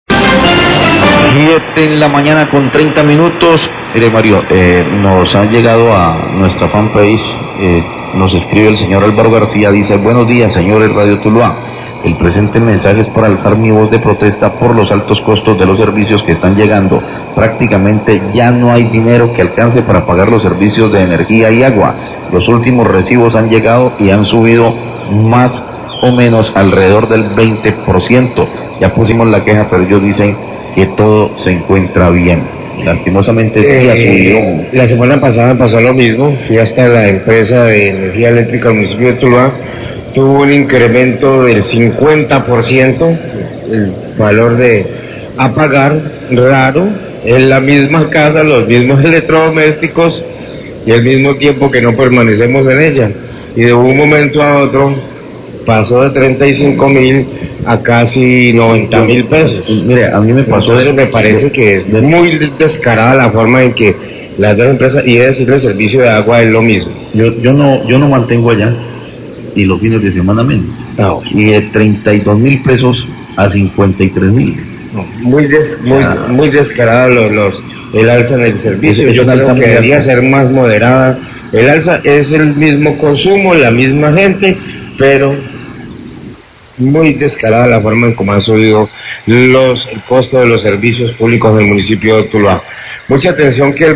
Radio
Periodistas hablan sobre su experiencia personal con las alzas en los servicios públicos y califican de descarados los incrementos de energía y agua.